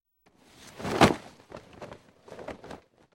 На этой странице собраны звуки, связанные с одеялом: шуршание ткани, легкое движение, уютное тепло.